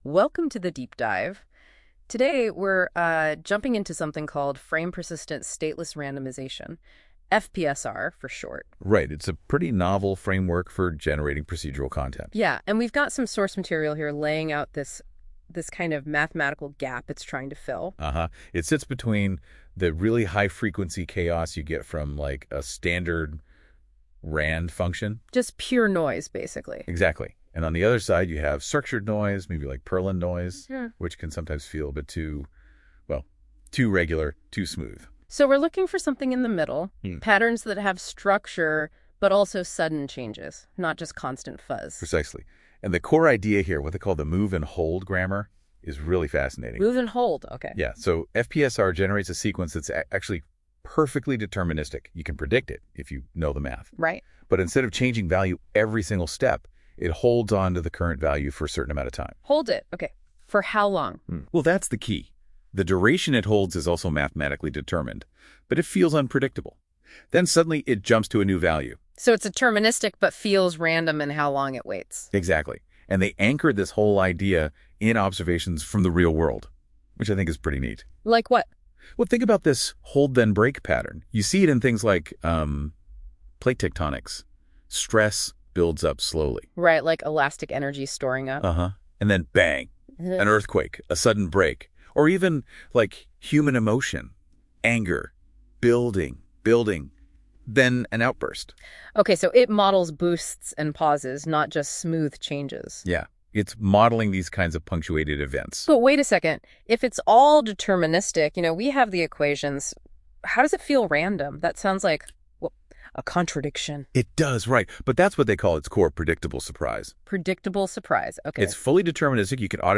A Podcast Style Audio Introduction
Here is a high-level podcast audio commentary of the following content.